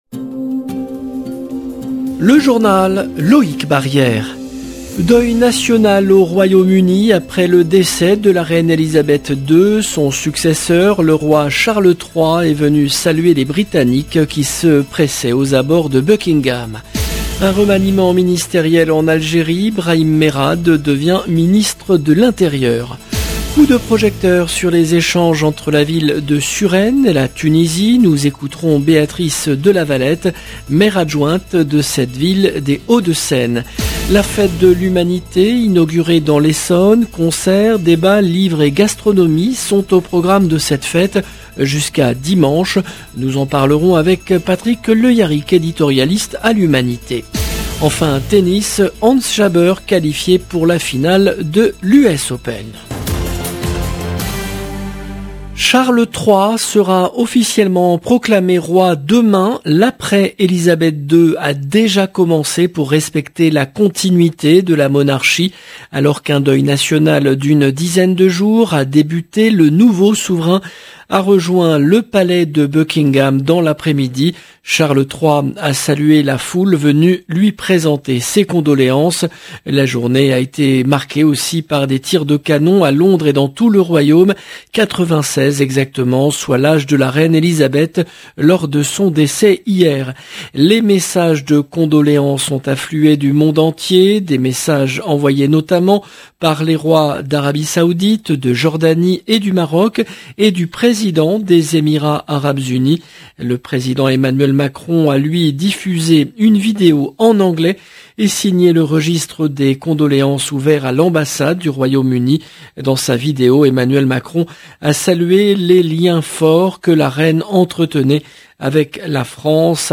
Nous écouterons Béatrice de Lavalette, maire adjointe de cette ville des Hauts-de-Seine.